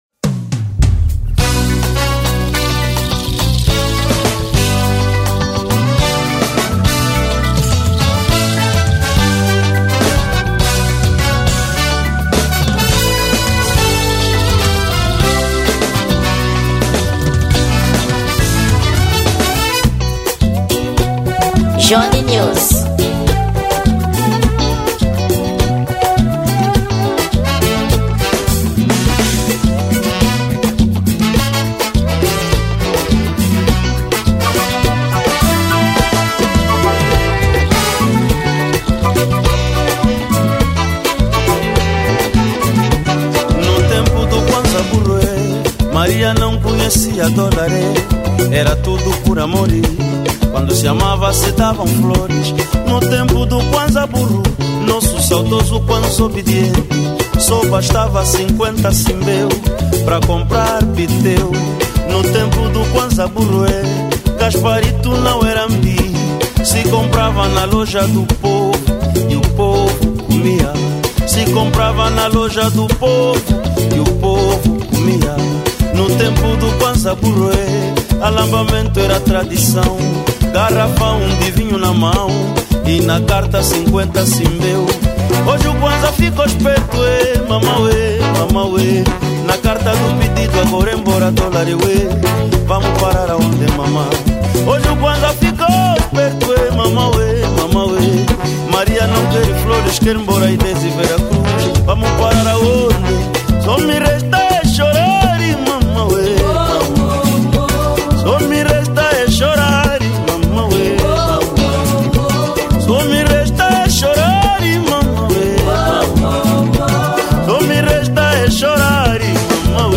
Gênero: Semba